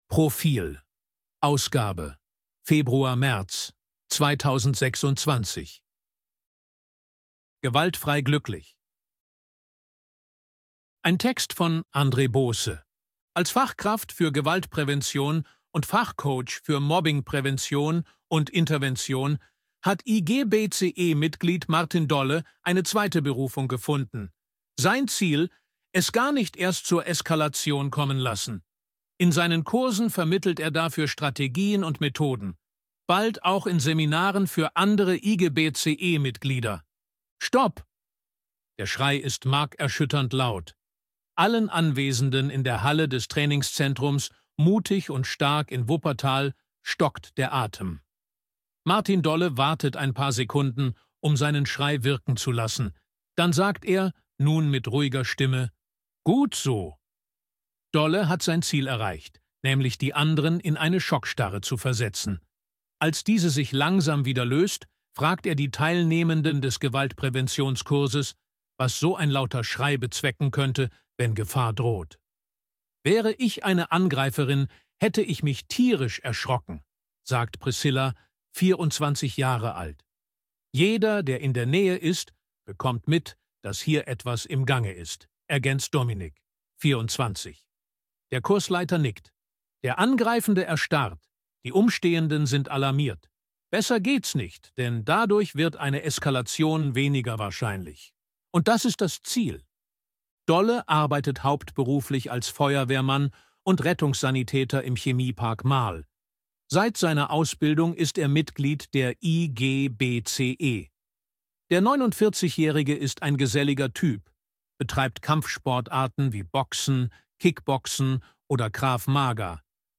Artikel von KI vorlesen lassen ▶ Audio abspielen
ElevenLabs_261_KI_Stimme_Mann_Portrait.ogg